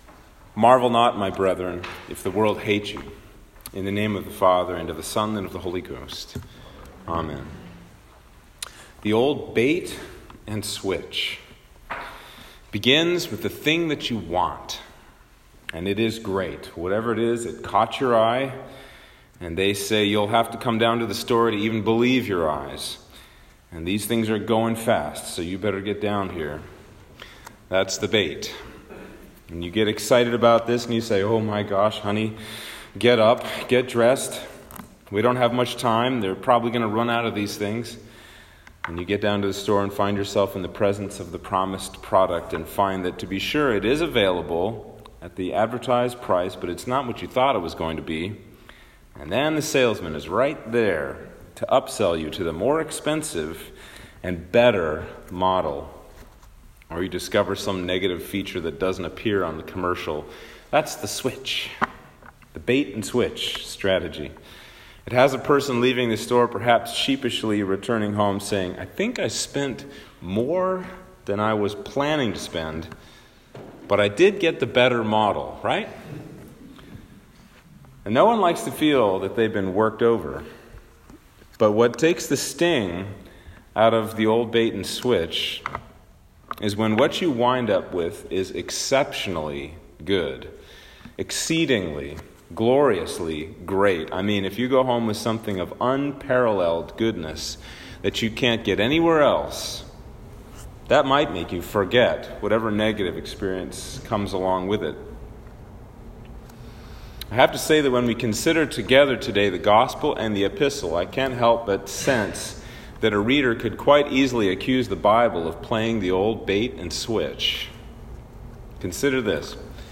Sermon for Trinity 2